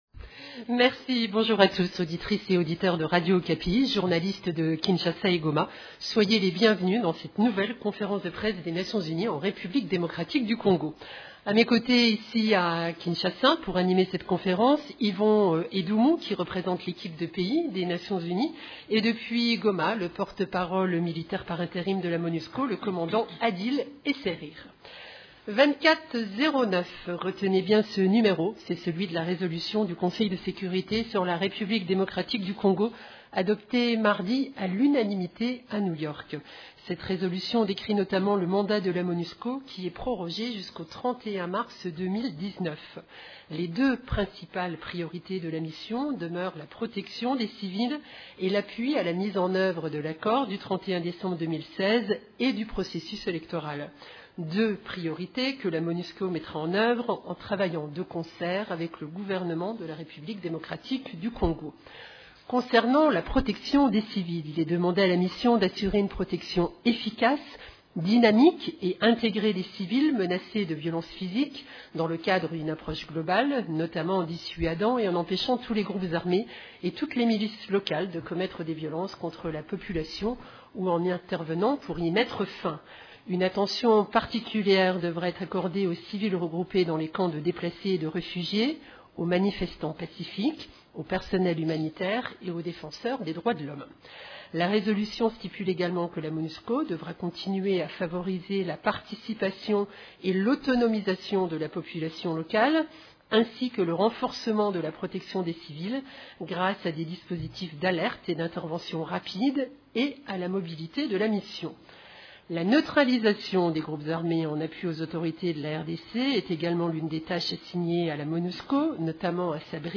Conférence de presse du jeudi 29 mars 2018
La conférence de presse hebdomadaire de l’ONU (en vidéo) à Kinshasa et à Goma a porté sur les activités d’agences des Nations unies en RDC.
Ecoutez la première partie de cette conférence de presse: